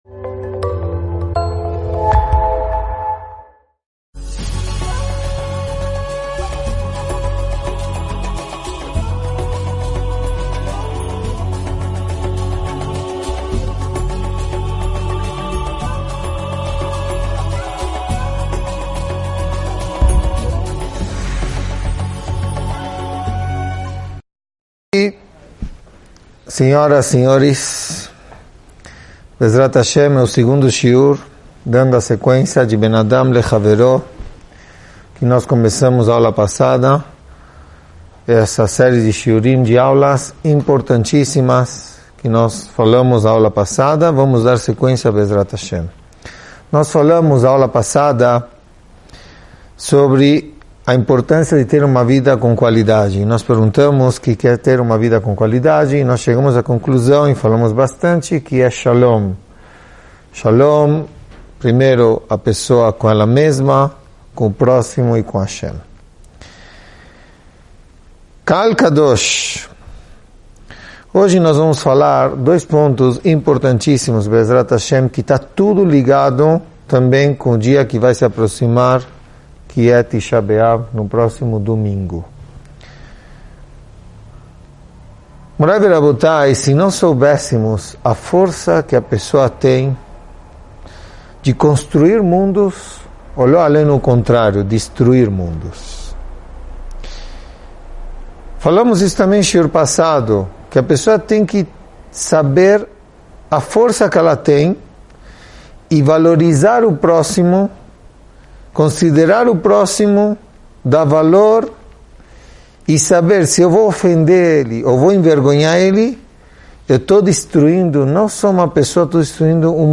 aula 2